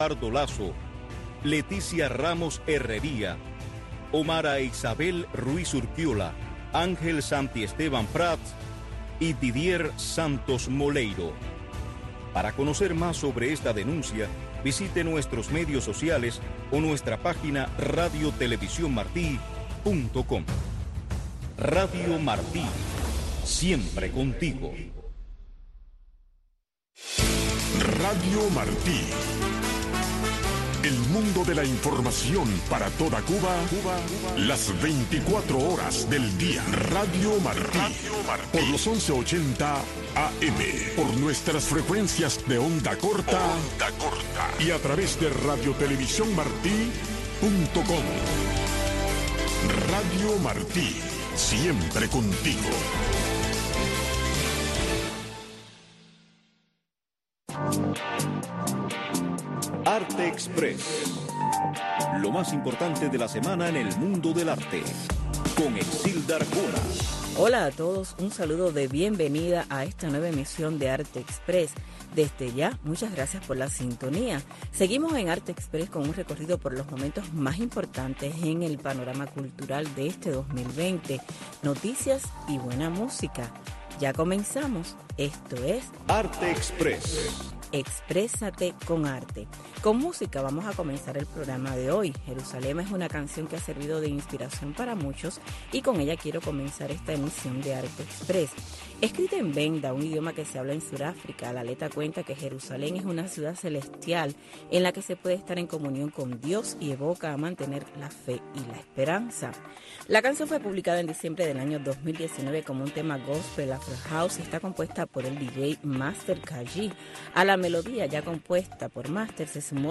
Arte Express, una revista informativa - cultural con noticias, eventos, blogs cubanos, segmentos varios, efemérides, música y un resumen de lo más importante de la semana en el mundo del arte.